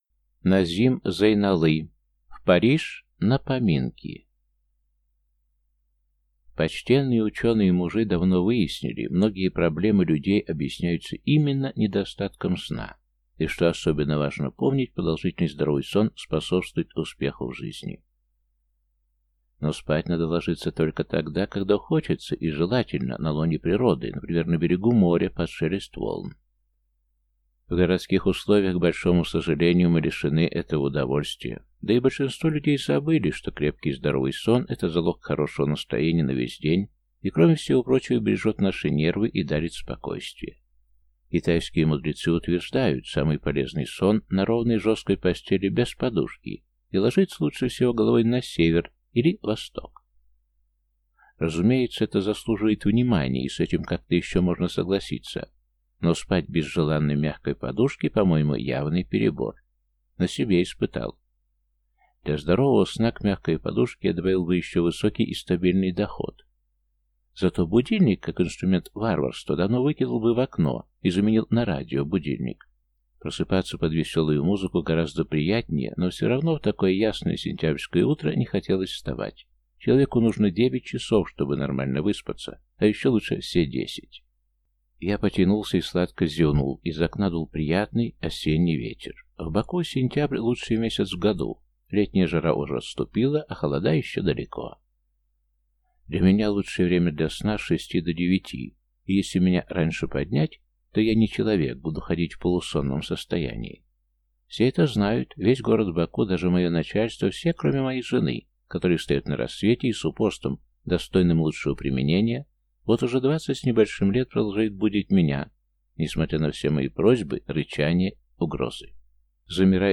Аудиокнига В Париж на поминки | Библиотека аудиокниг